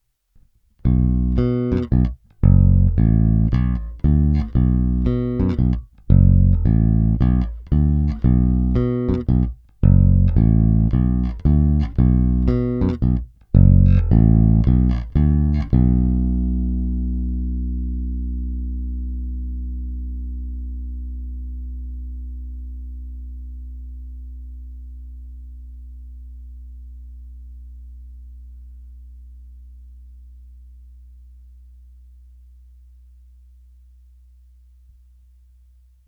I přestože jsou na base půl roku staré struny, což u Elixirů v zásadě nic neznamená, je slyšet, že nové snímače mají o něco mohutnější basy a brilantnější výšky, než ty původní mexické.
Oba snímače – původní